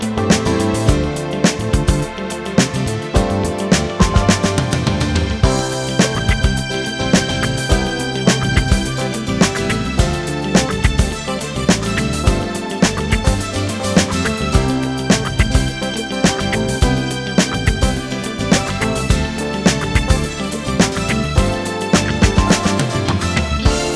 no Backing Vocals Disco 3:14 Buy £1.50